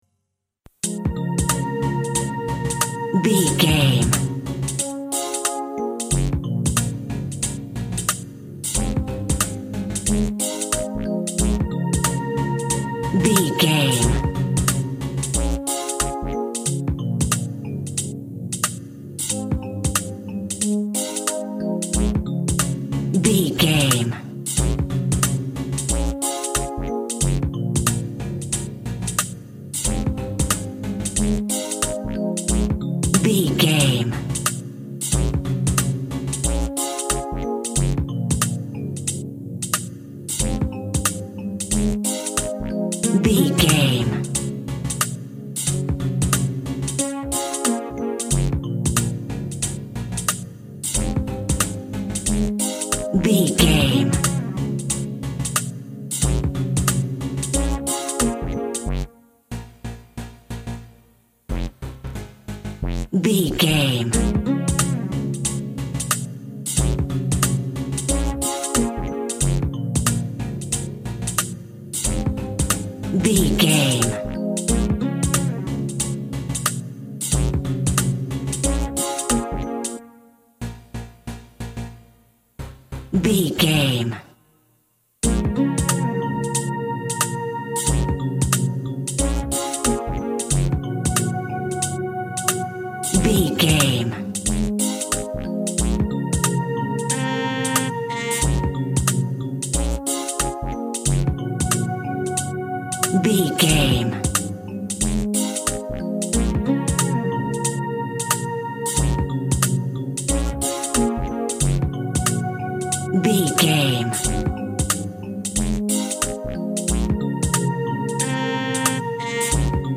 Hip Hop Lost In Music.
Aeolian/Minor
B♭
synth lead
synth bass
hip hop synths
electronics